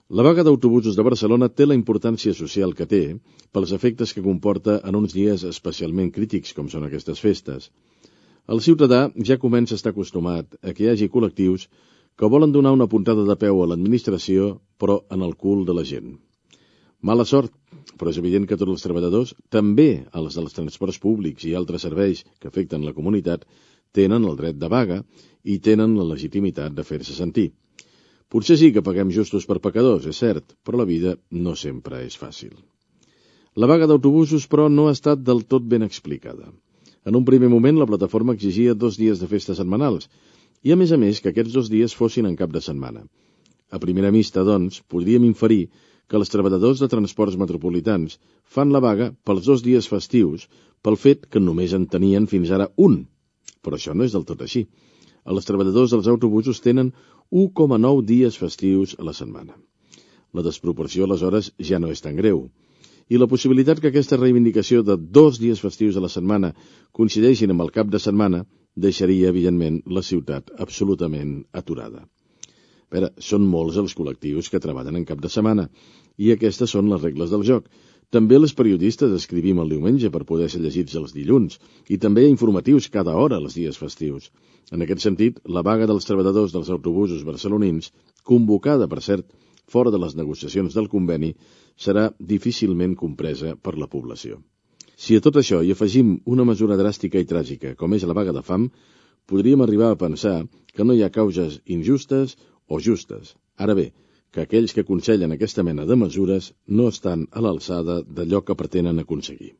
Editorial sobre la vaga d'autobusos de Barcelona.
Informatiu